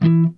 JAZZRAKE 2.wav